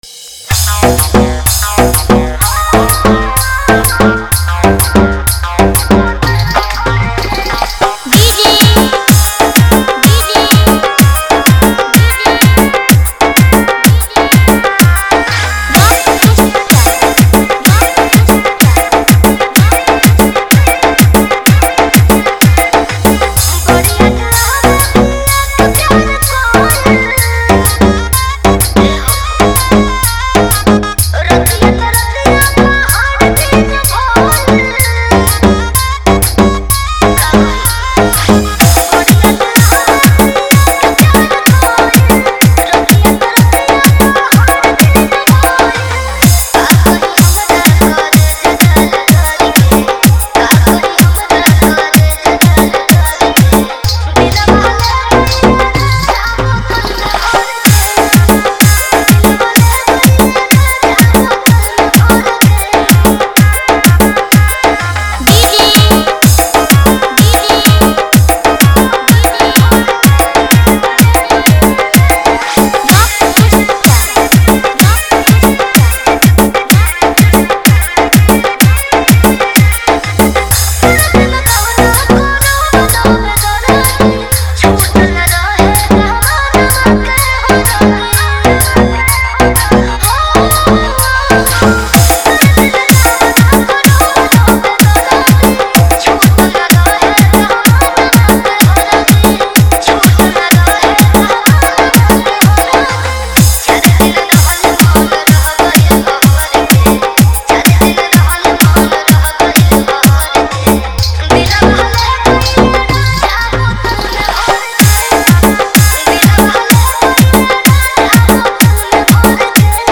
Category:  Bhojpuri Dj Remix